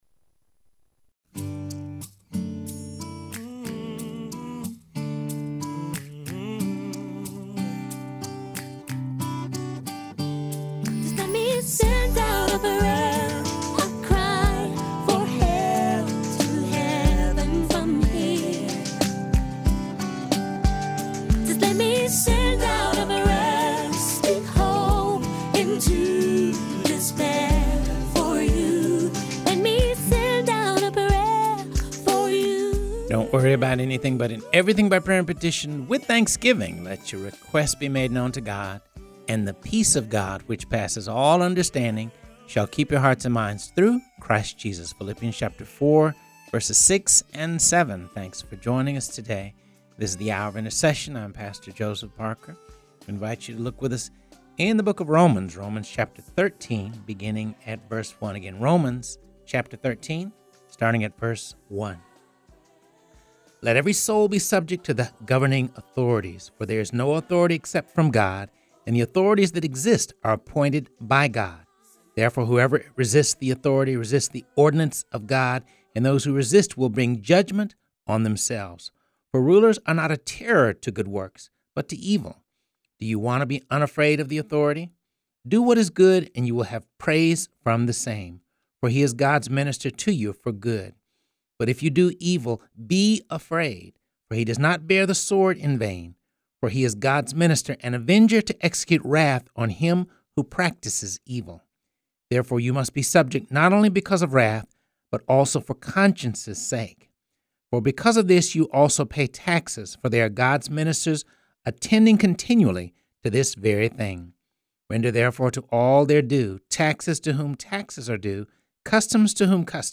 talks with Mound Bayou Mississippi Mayor and Pastor Darryl Johnson about being a disciple of Christ in all aspects of life